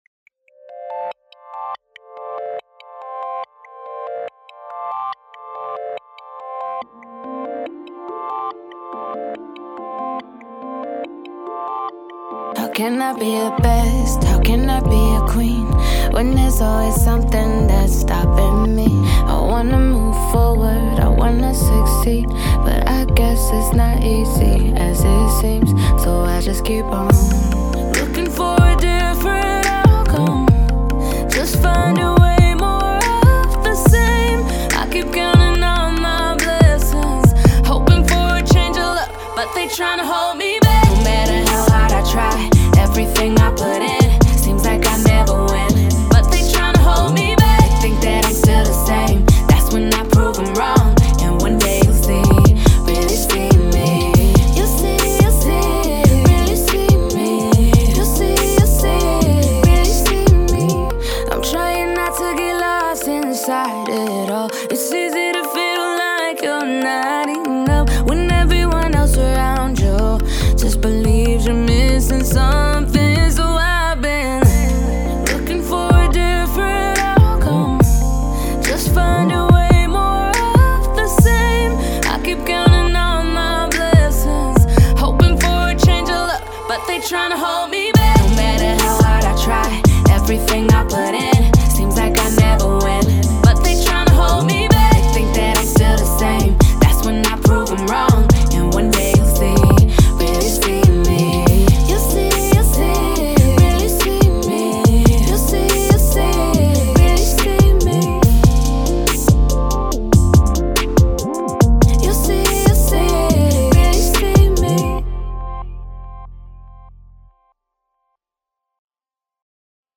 R&B, Soul
F maj